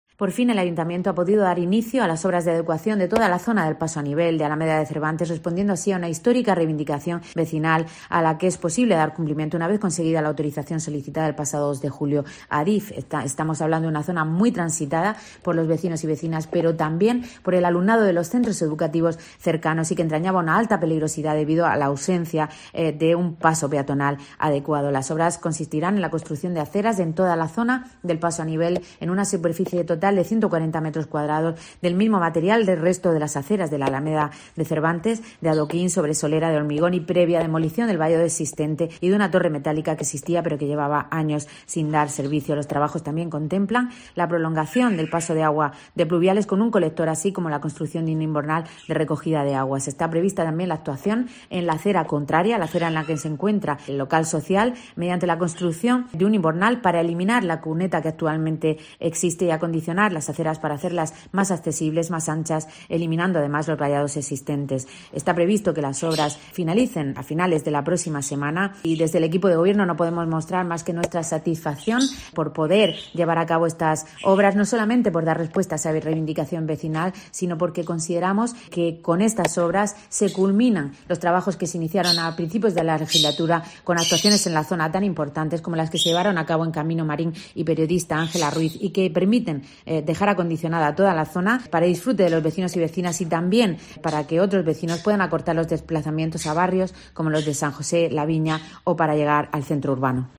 Isabel Casalduero, portavoz del PSOE sobre obras paso a nivel